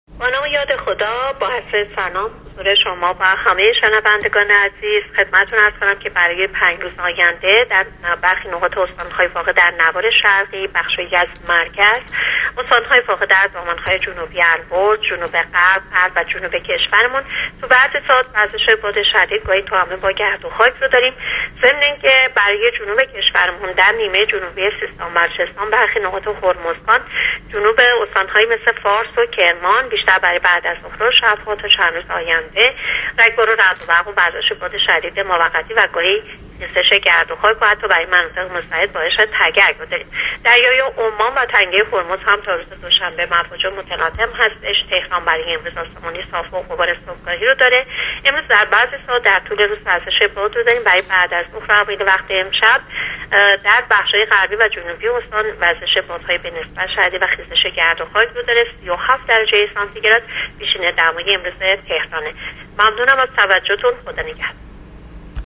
کارشناس سازمان هواشناسی در گفت‌وگو با رادیو اینترنتی پایگاه خبری وزارت راه‌ و شهرسازی، آخرین وضعیت آب‌وهوای کشور را اعلام کرد.
گزارش رادیو اینترنتی پایگاه خبری از آخرین وضعیت آب‌وهوای هجدهم تیرماه؛